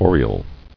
[o·ri·el]